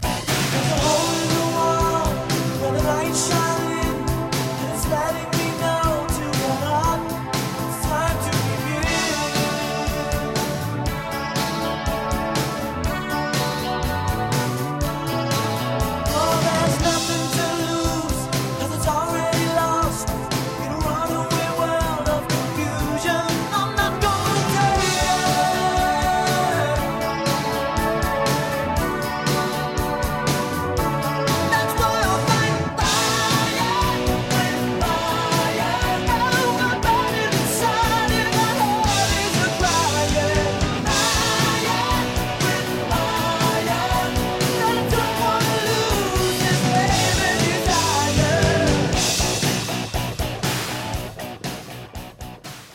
OLD SCHOOL ROCK no. 3